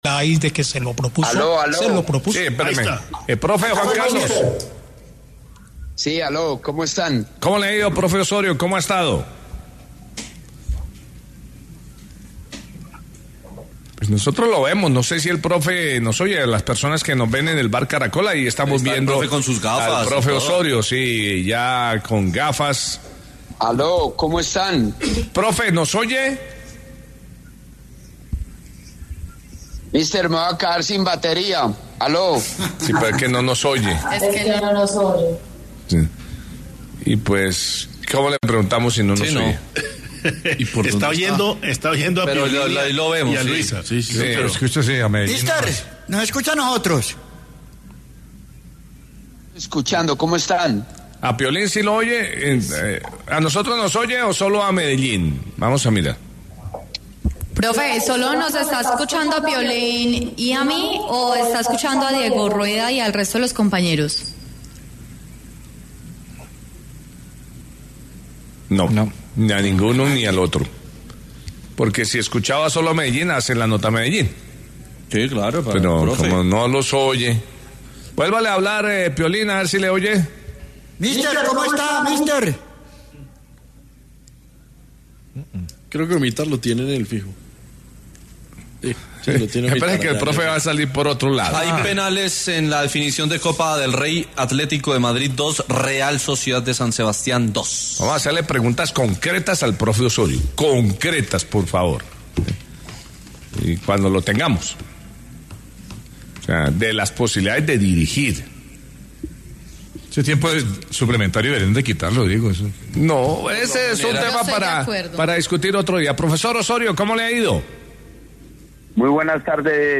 Juan Carlos Osorio dejó en claro, en dialogó con El VBar Caracol, que sigue en firme su deseo de dirigir a la Selección Colombia. De igual manera, Osorio habló de un posible regreso a Nacional, de las opciones que contempla para volver a entrenar y dio su opinión sobre algunos jugadores del combinado nacional, entre ellos James, Falcao y Ospina.